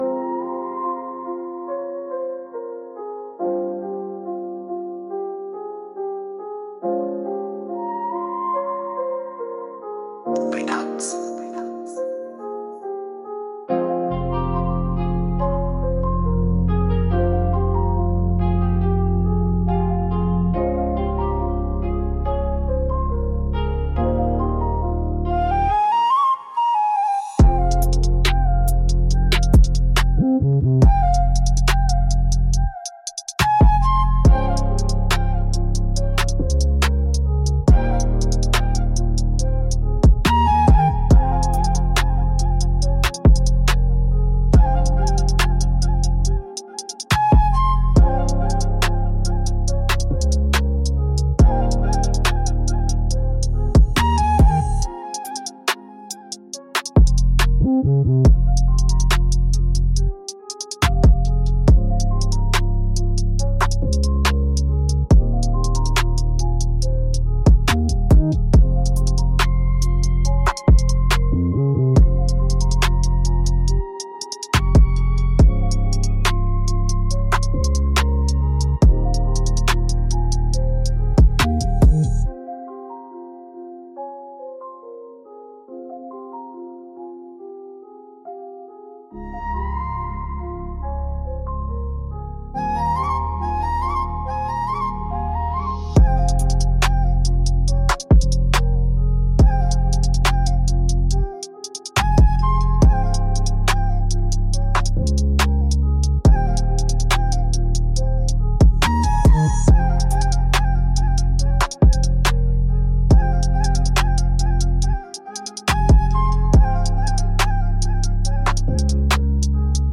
F Minor -140 BPM
Drill
Trap